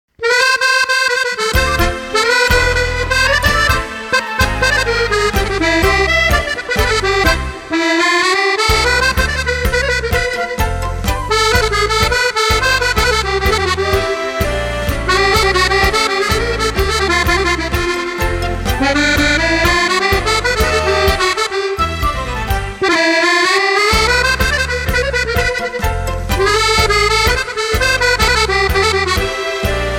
Genre : Tango.